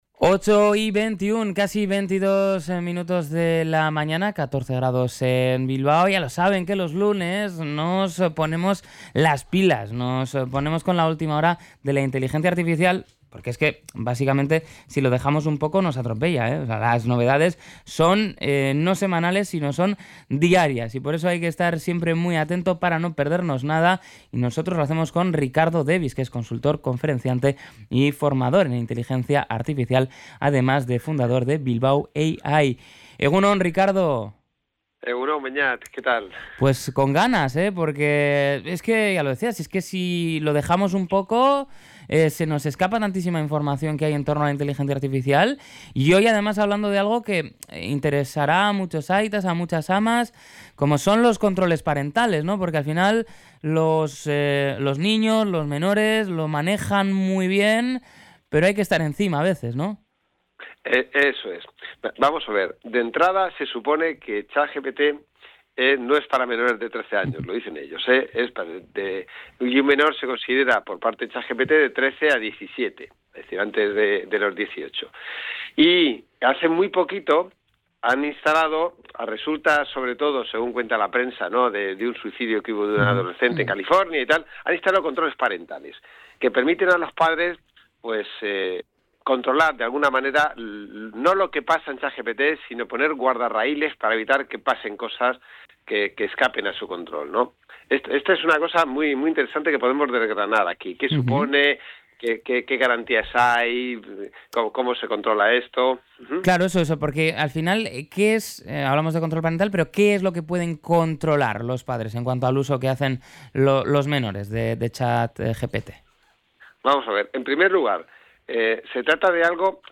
Durante la conversación se ha subrayado que ChatGPT se ha convertido en la “navaja suiza” de las y los más jóvenes: sustituye búsquedas y consultas cotidianas, y facilita tareas académicas.